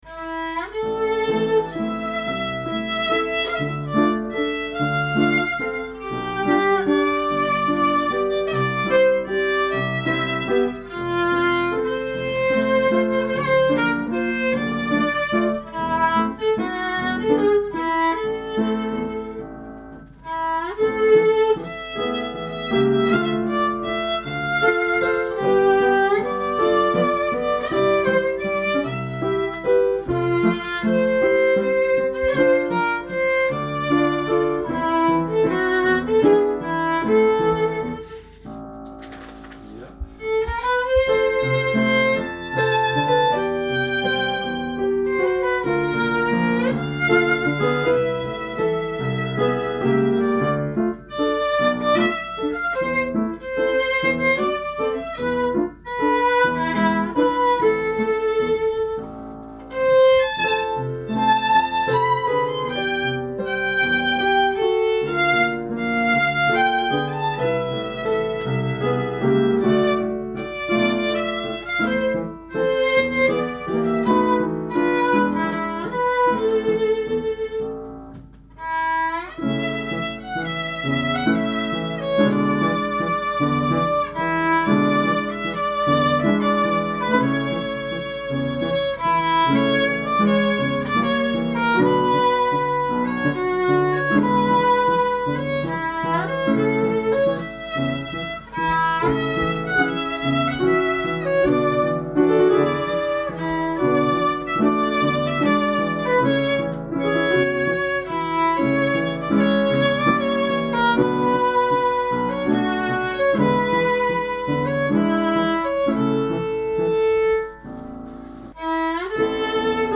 (piano) and myself (violin), transferred from an old minidisc recording (2004?), using Focusrite.